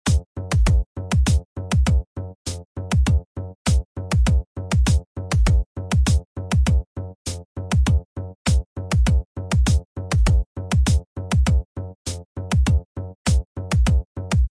Download Shark sound effect for free.